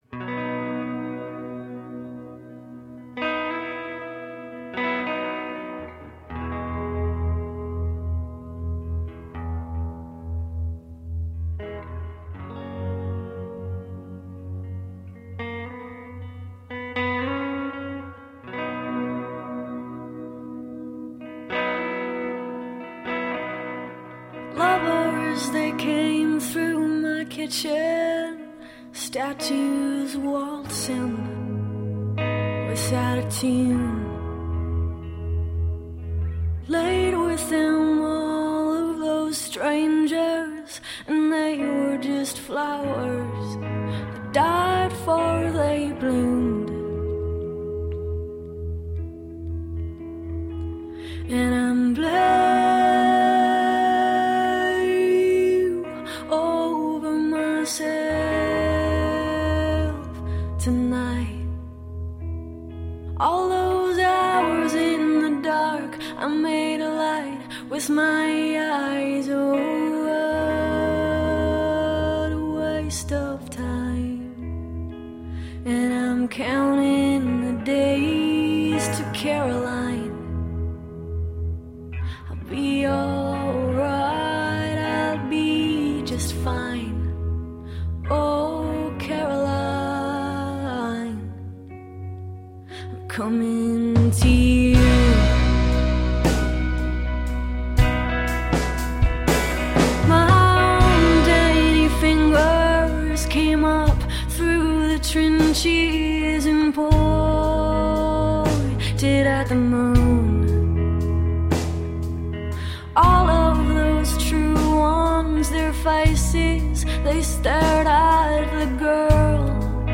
Indie sound, southern soul.
Tagged as: Alt Rock, Folk-Rock